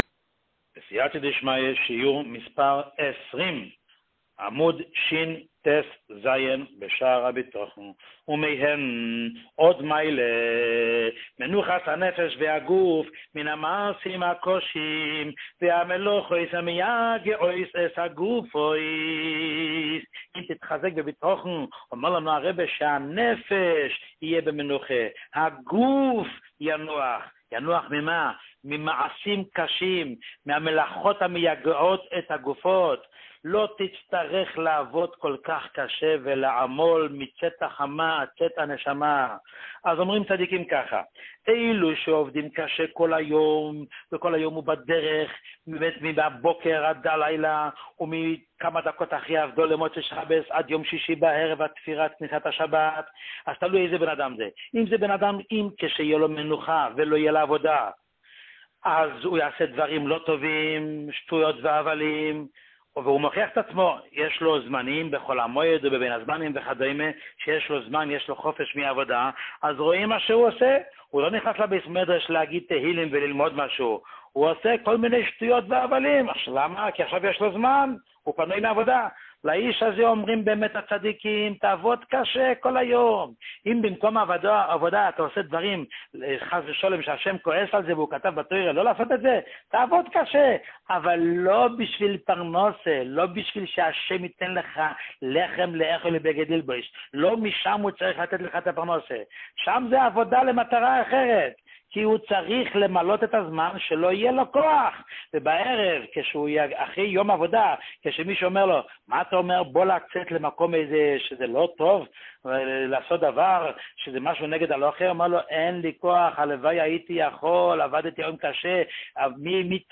שיעורים מיוחדים